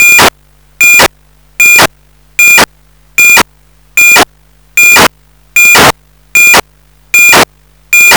Ich habe mir die gesendenten Daten mal mit einem Funkscanner und Soundkarte aufgenommen und versucht zu analysieren. Die Wav-Datei beinhaltet eine komplette Übertragung für alle meine 11 Rolladen, dass sie auf gehen sollen.
Preamble ca 0,18 sec. immer Die Übertragung erfolgt zeitlich verzögert über alle drei Kanäle zur Sicherstellung, dass die Daten übertragen wurden.